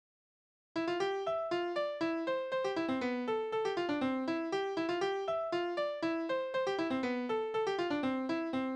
« O-10058 » Mädel, putz dich Tanzverse: Rheinländer Mädel, putz dich, kämm dich, mach dich schön, wir wollen beid auf den Tanzbod'n gehn Mädel, usw. Tonart: C-Dur Taktart: 4/8 Tonumfang: Oktave, Quarte Besetzung: vokal Externe Links